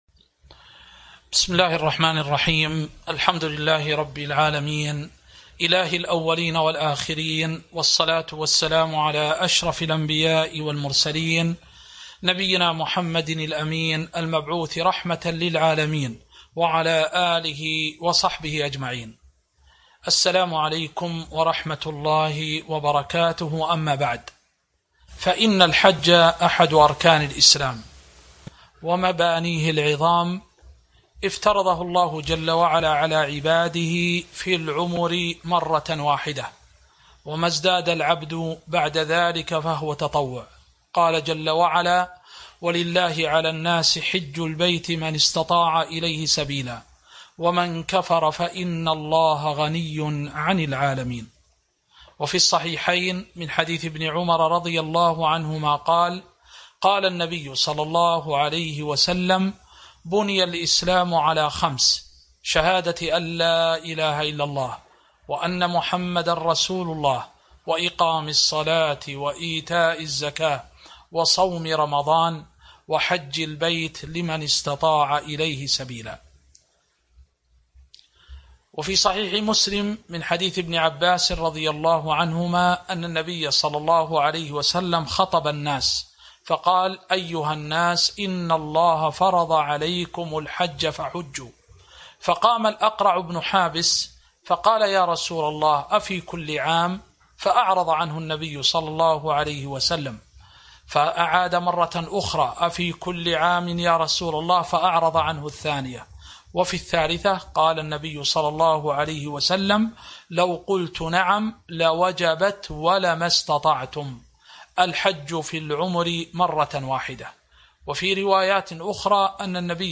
تاريخ النشر ٣ ذو الحجة ١٤٤١ هـ المكان: المسجد النبوي الشيخ